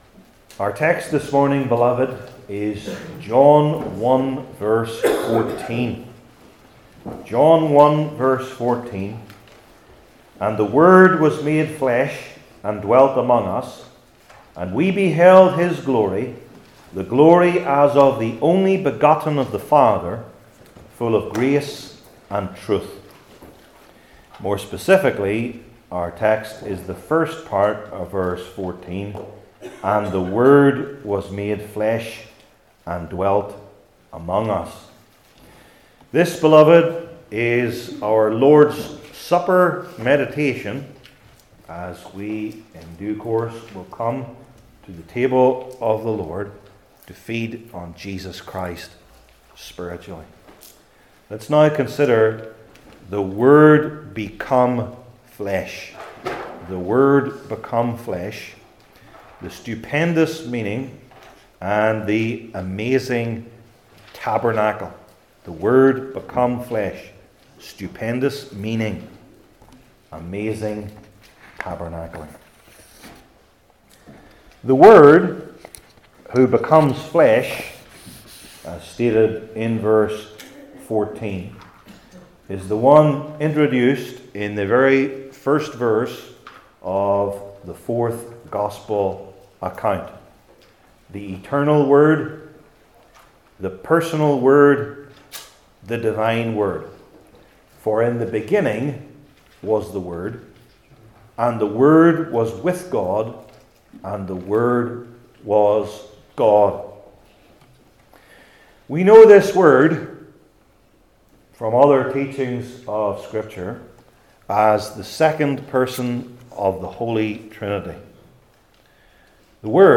New Testament Individual Sermons I. The Stupendous Meaning II.